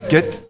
ket-pron.mp3